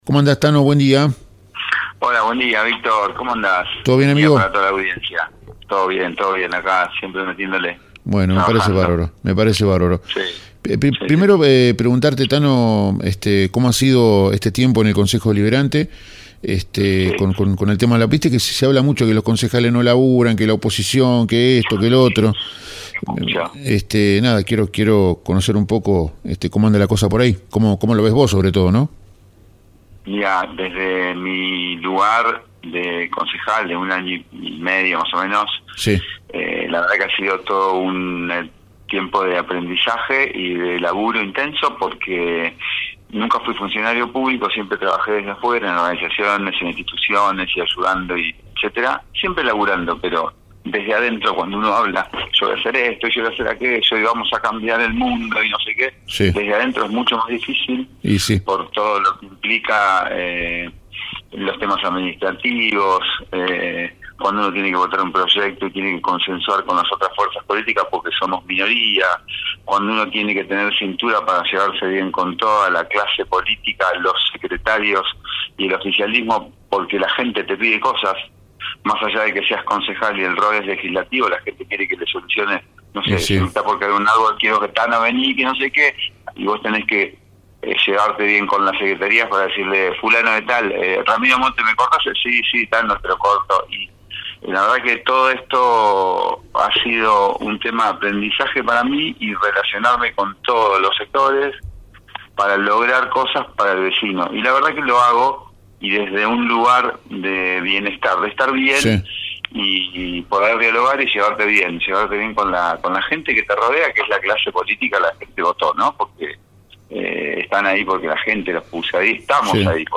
Leo Napoli actual concejal del justicialismo habló este lunes con Pinamardiario